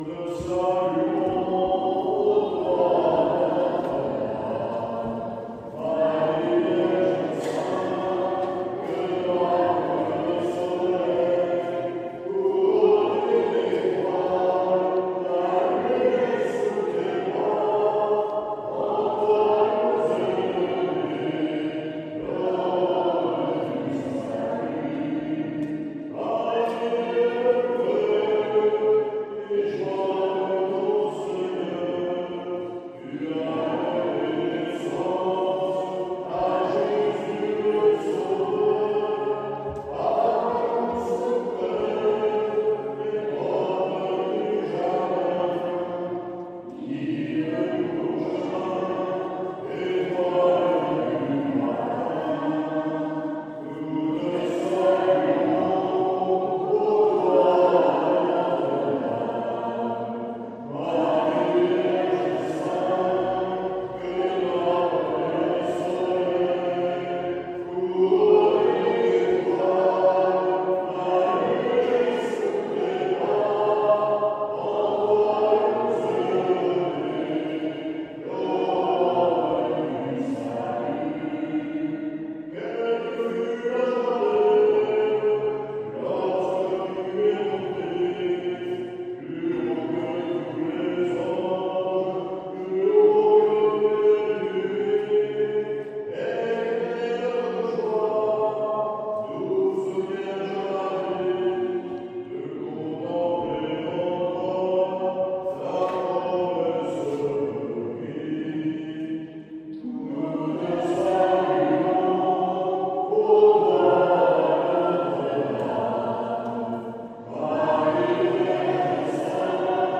Une messe a eut lieu dans cette église le 15/08/2025 à 10H30
un cinquantaine de fidèles ont participé à cette office